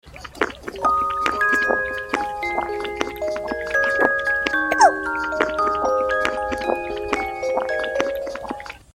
Cute bunny sound effects free download